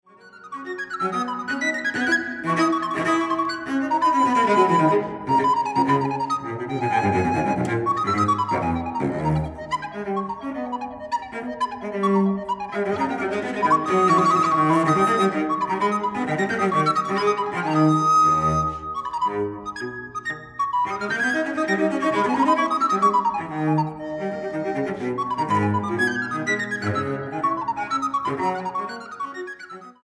flautas